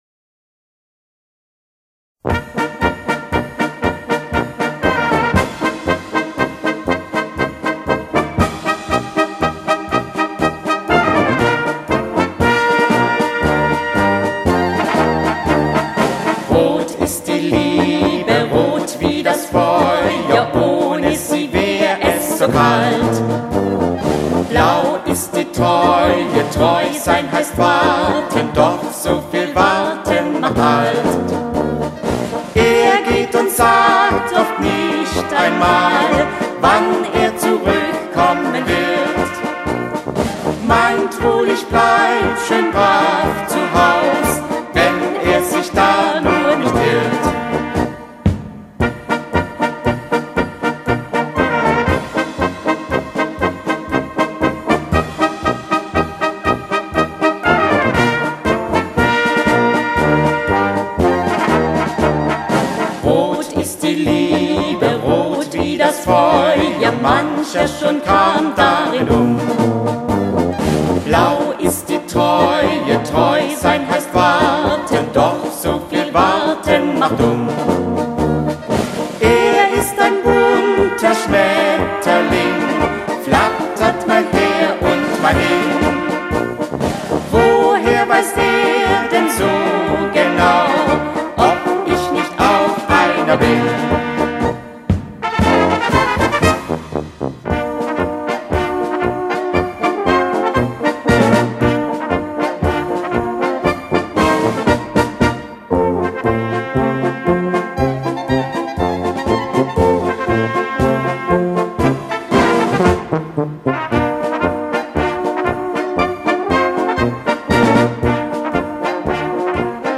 Polka mit Gesang